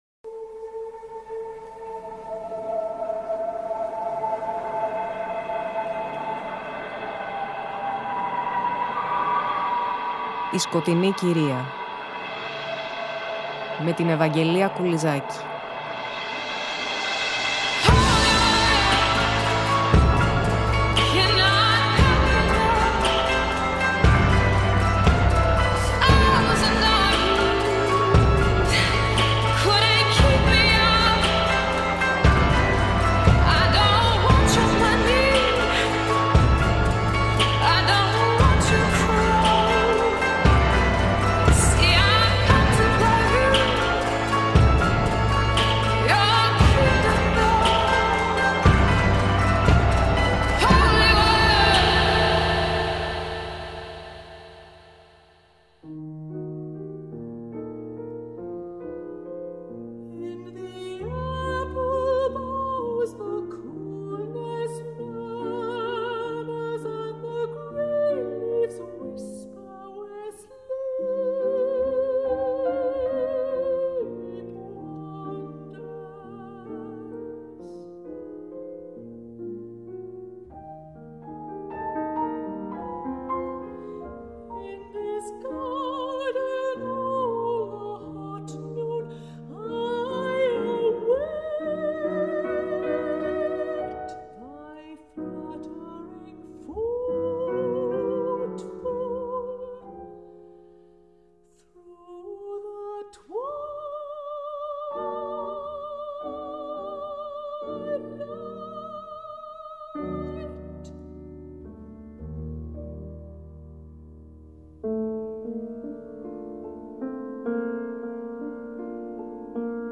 Στη συγκεκριμένη εκπομπή πλαισιώνουμε μουσικά με επιλογές από τον κύκλο τραγουδιών του Sir Granville Bantock , βασισμένων σε ποίηση της Σαπφούς μεταφρασμένη από τον Henry Thornton Wharton .